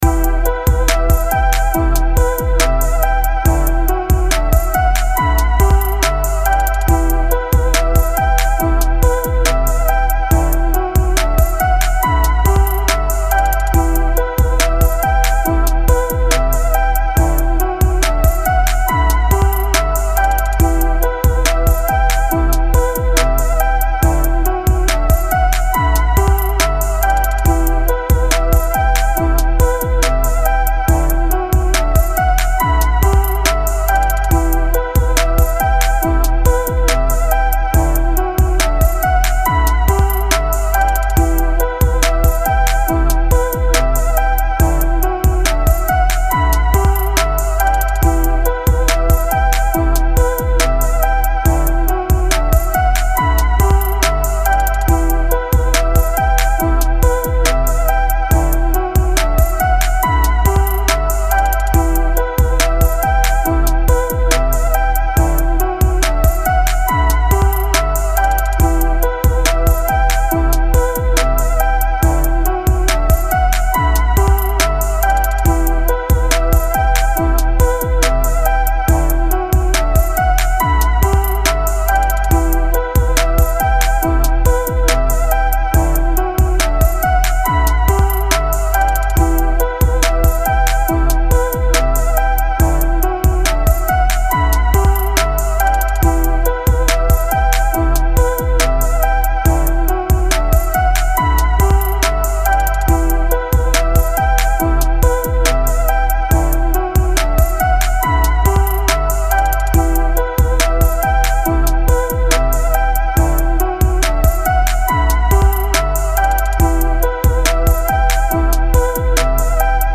Home > Music > Beats > Electronic > Smooth > Medium